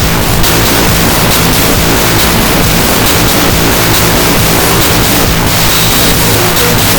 amiga dirty distorted error industrial loop messy noise sound effect free sound royalty free Memes